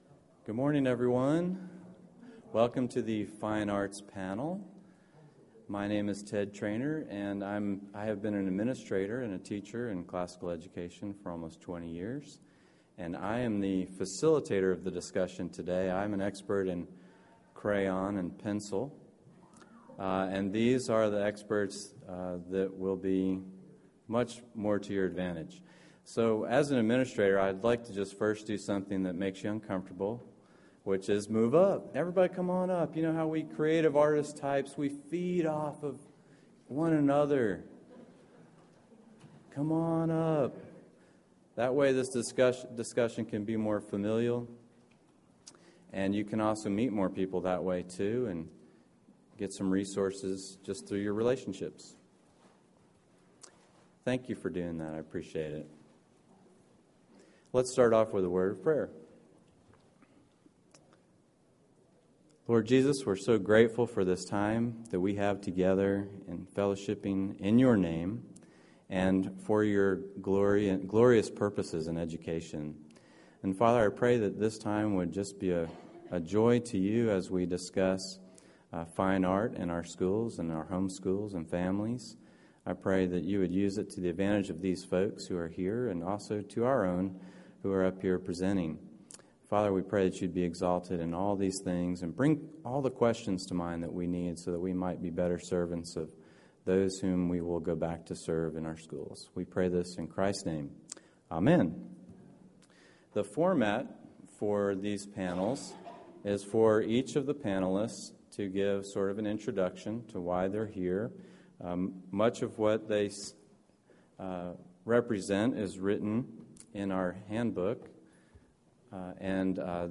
The Fine Arts in Classical Christian Education – Panel Discussion | ACCS Member Resource Center
2014 Workshop Talk | 1:00:19 | Art & Music
Panel The Fine Arts in Classical Christian Education.mp3